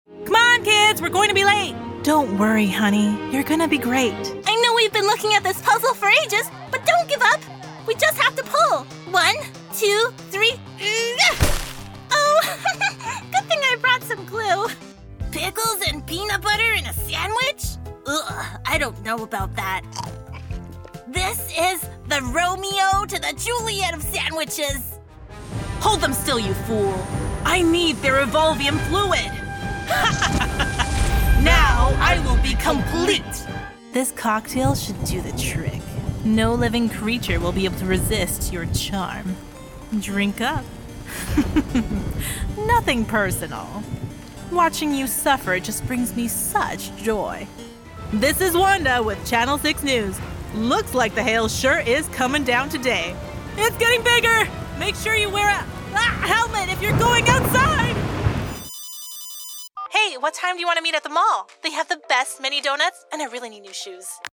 Teenager, Young Adult, Adult
Has Own Studio
ANIMATION 🎬